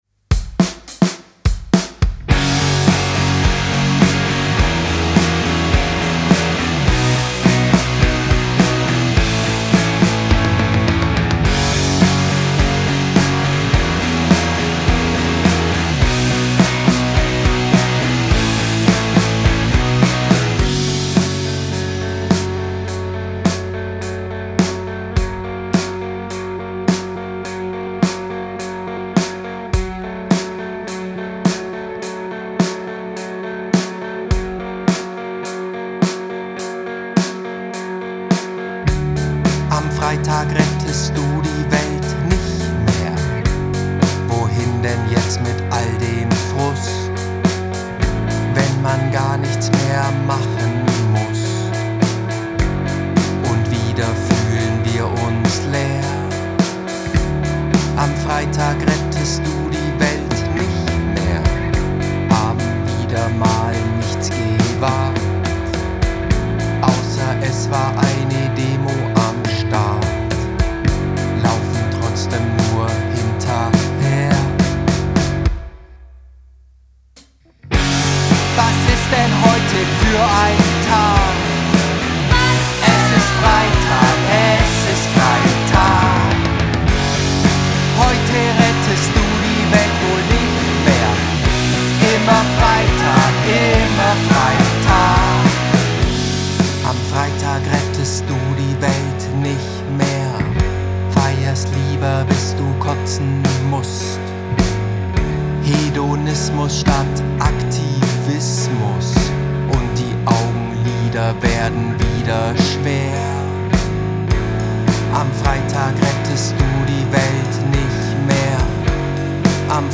Gitarre
Bass, Backing Vocals
Schlagzeug
Es wird wird laut, verrückt, schrammlig.